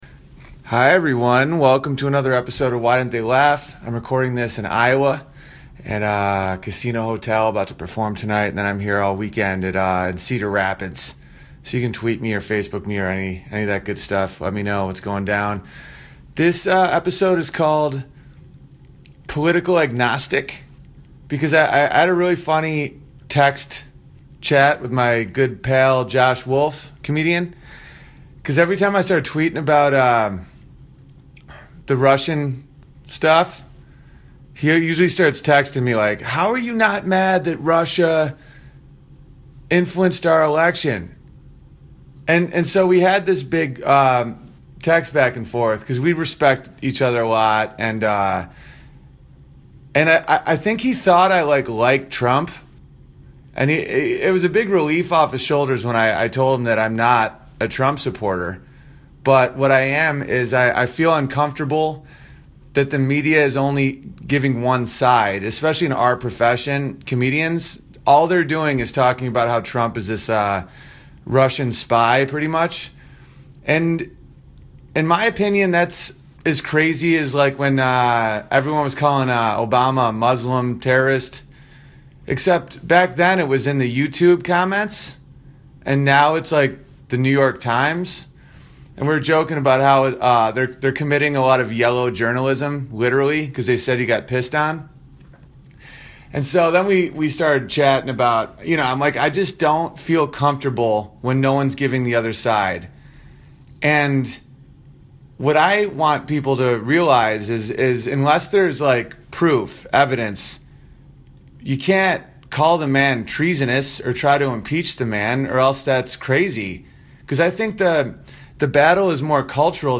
No stand up just book chat and a think I wrote about virtual reality.